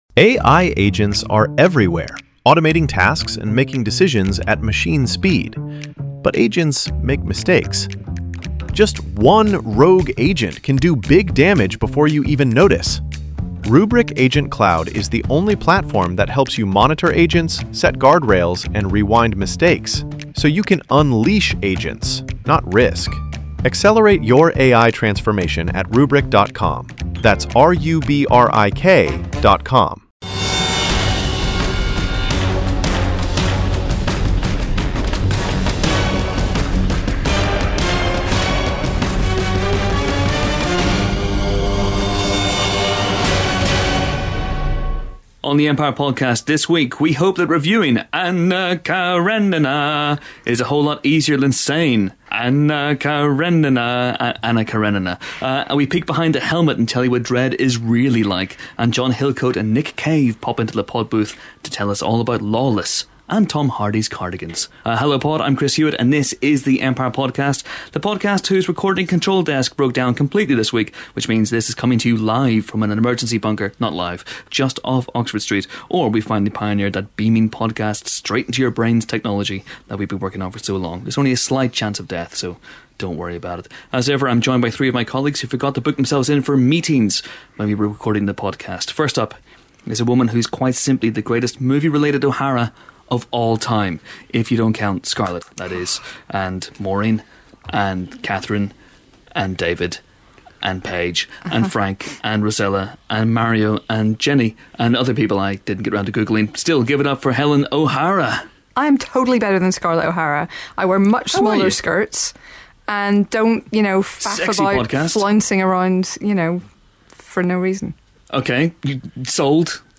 During the recording of Empire's 27th podcast, the sound desk exploded (slightly), forcing the team to relocate to a different studio. Because of this, the audio on our interview with Lawless' director and writer (respectively), John Hillcoat And Nick Cave, is slightly on the shonky side - but fortunately they're both excellent and informative humans beings, so it all evens out.